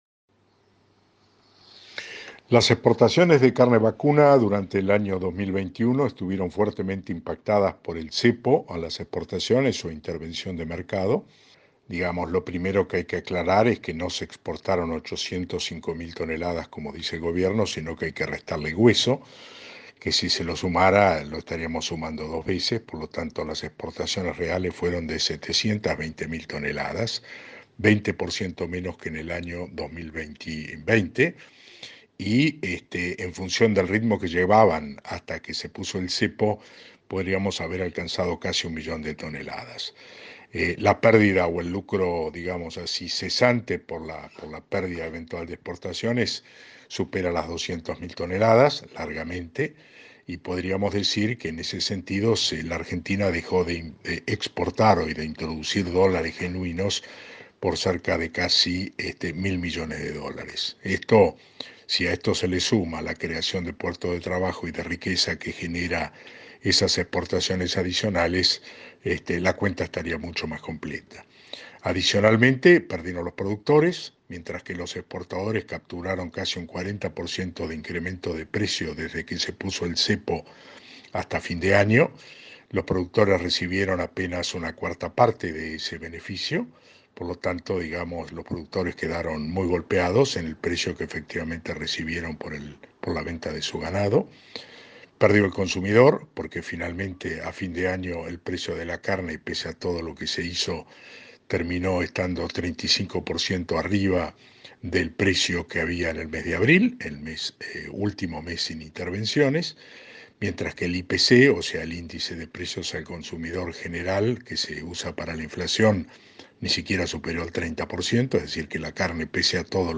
En diálogo con EL CAMPO HOY, aclaró que las exportaciones de carne vacuna durante 2021 estuvieron fuertemente impactadas por el cepo o la intervención del mercado.